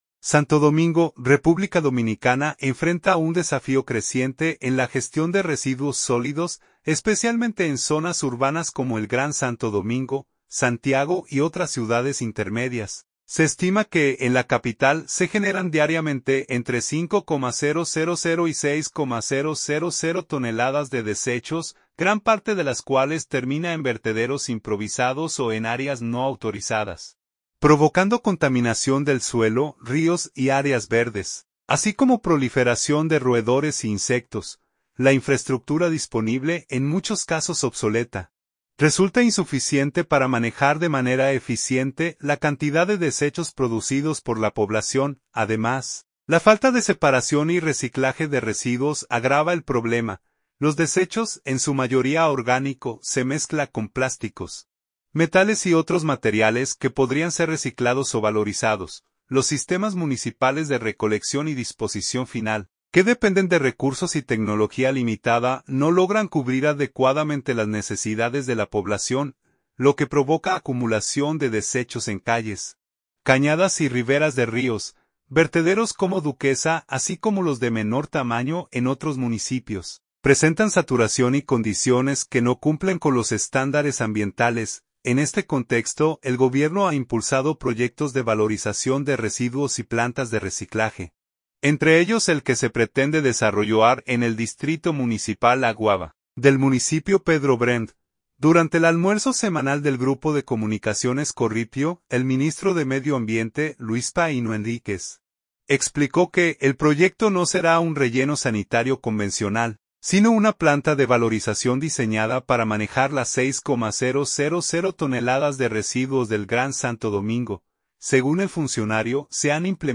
Durante el Almuerzo Semanal del Grupo de Comunicaciones Corripio, el Ministro de Medio Ambiente, Luis Paíno Henríquez, explicó que el proyecto no será un relleno sanitario convencional, sino una planta de valorización diseñada para manejar las 6,000 toneladas de residuos del Gran Santo Domingo.